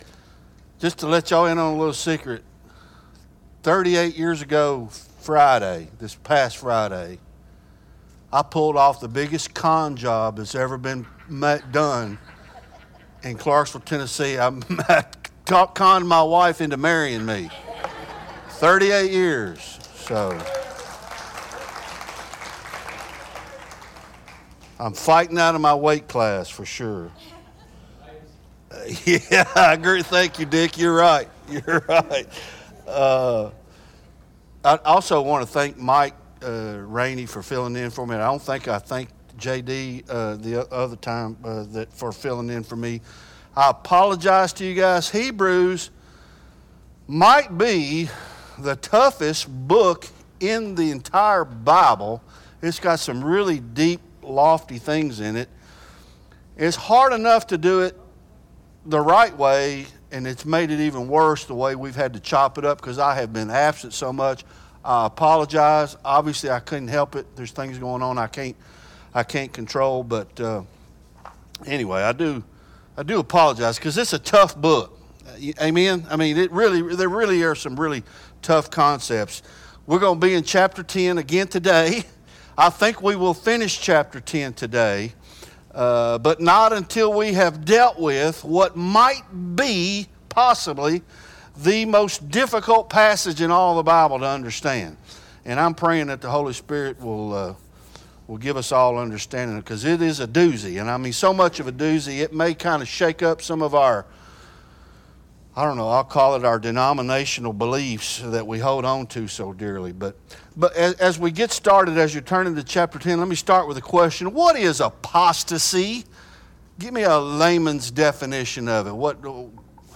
Bible Study Hebrews Ch 10 Part 1